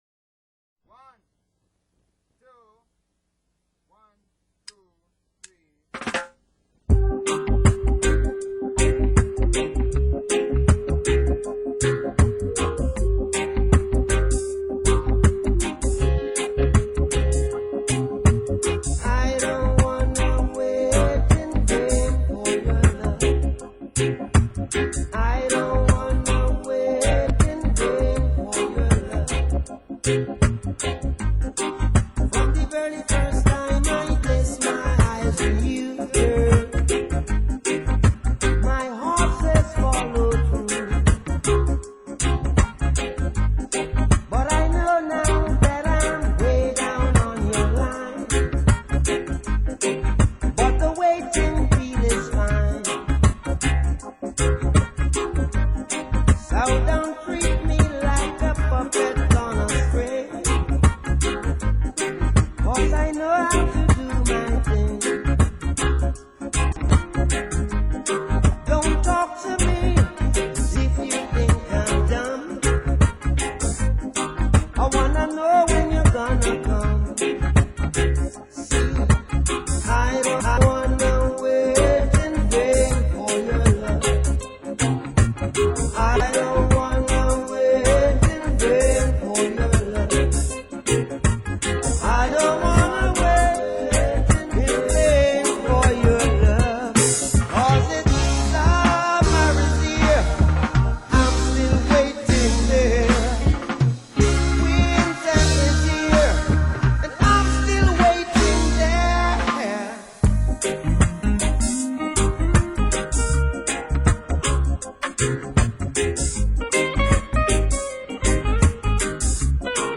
For a warm chilled out ride of our 1980’s family blog
as you listen to the Jamaican legend singing